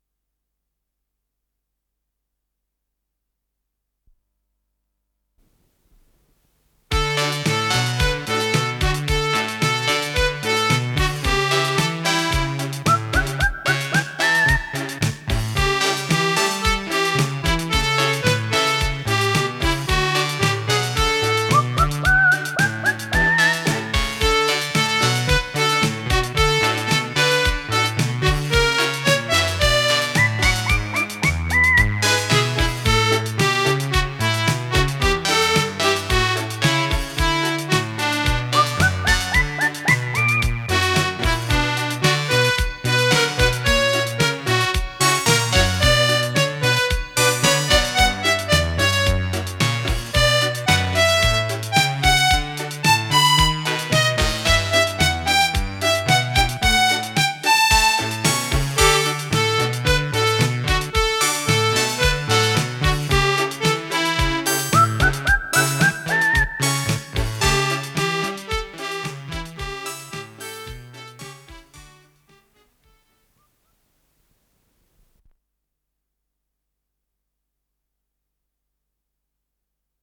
ПодзаголовокИнструментальная пьеса, соль мажор
Скорость ленты38 см/с
ВариантДубль моно